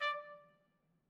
hover-sound.mp3